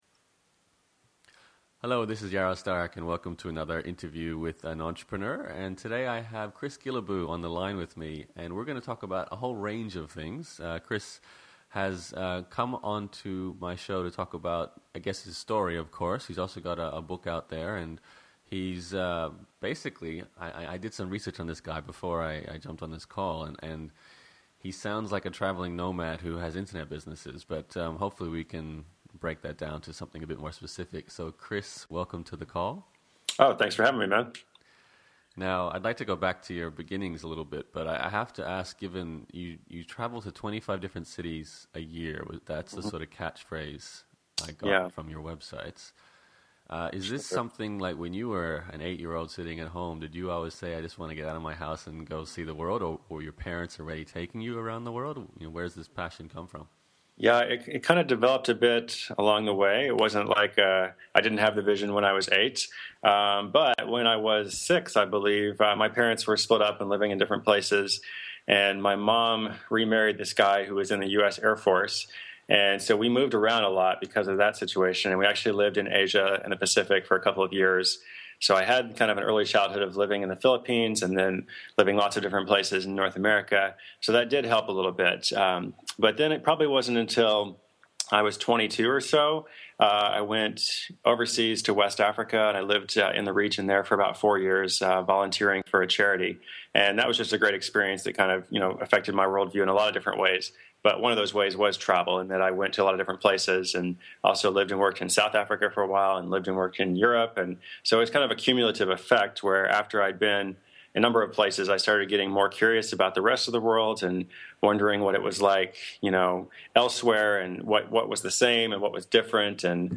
An Unusual Interview With World Traveling, Book Writing, Internet Giant, Chris Guillebeau
This is no ordinary podcast interview with Chris Guillebeau.